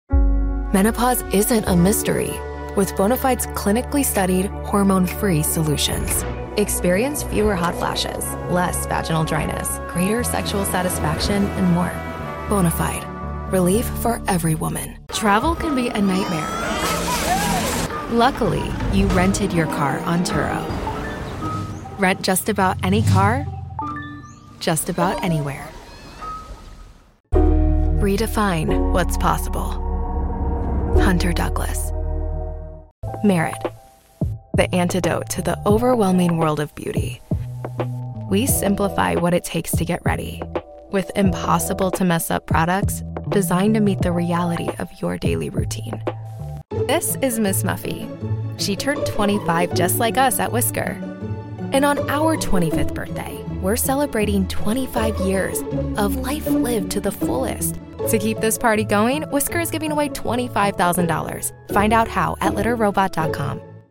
Hire American English Voice Artists